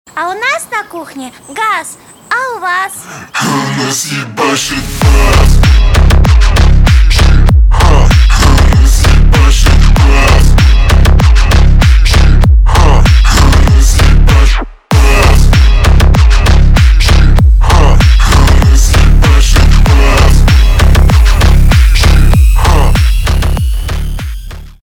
bass house , рэп
клубные , мощные